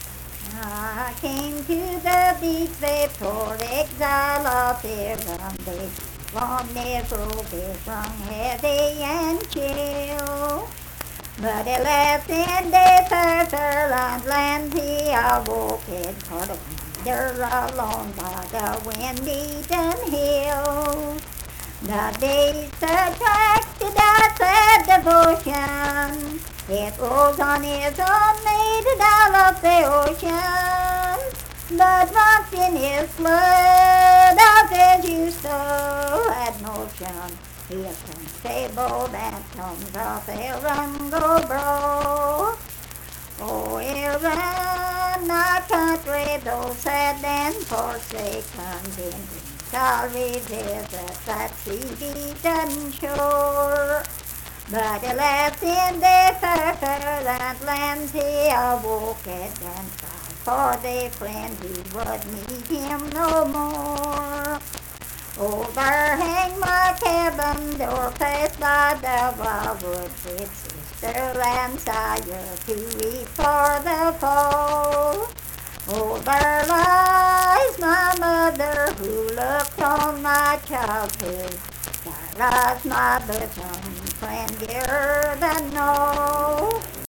Unaccompanied vocal music performance
Verse-refrain 6(4) & R(4).
Voice (sung)